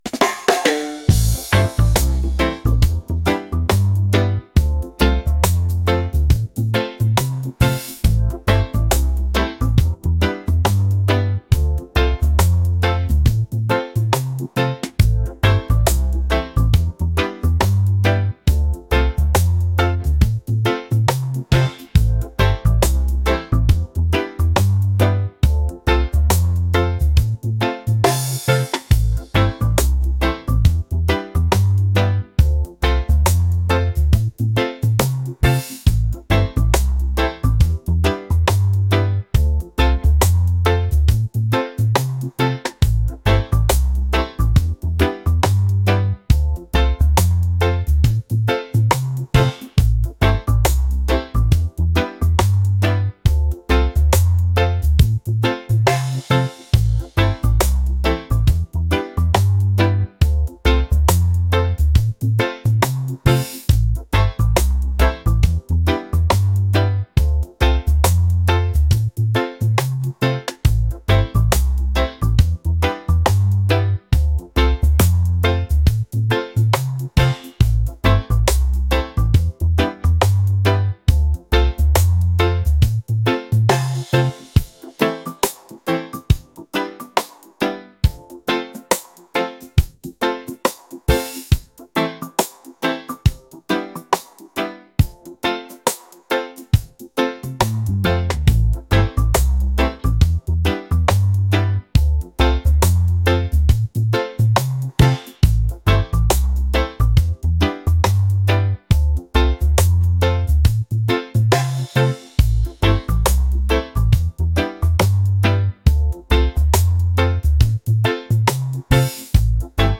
laid-back | smooth | reggae